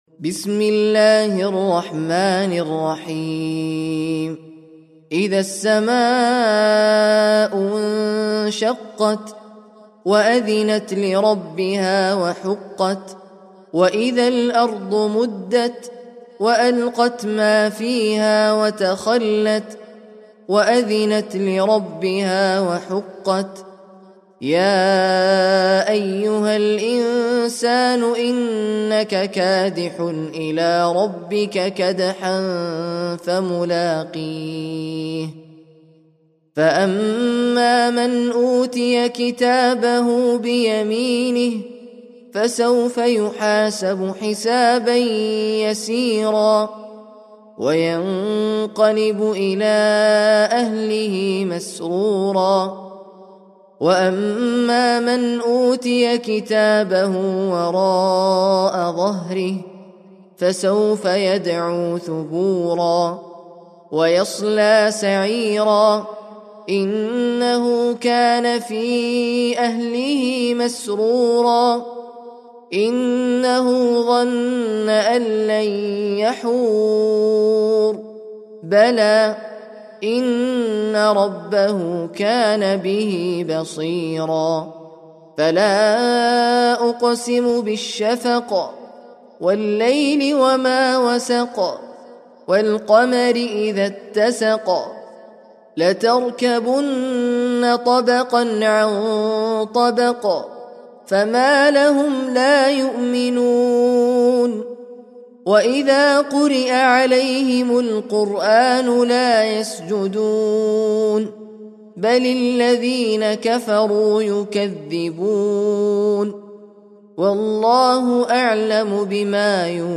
Sûrat Al-Inshiqaq (The Splitting Asunder) - Al-Mus'haf Al-Murattal
very high quality